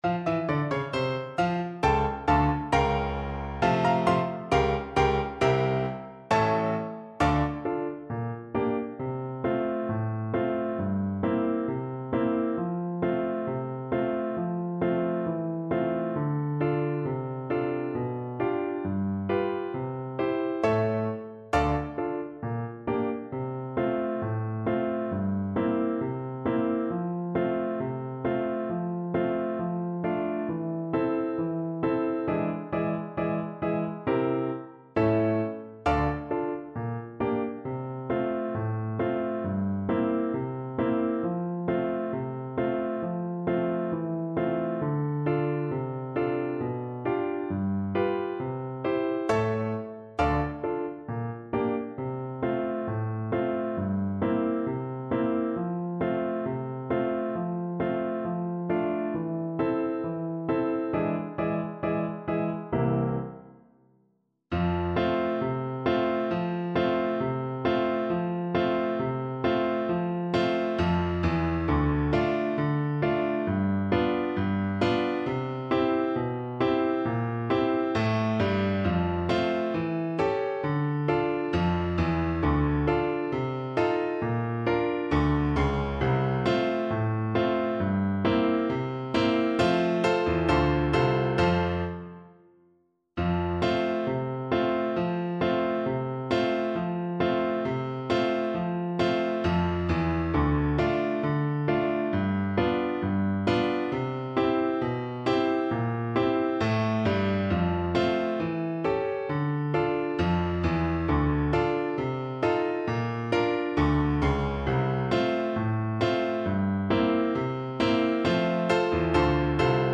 Clarinet
2/2 (View more 2/2 Music)
F major (Sounding Pitch) G major (Clarinet in Bb) (View more F major Music for Clarinet )
Classical (View more Classical Clarinet Music)
handsacrossthesea_CL_kar3.mp3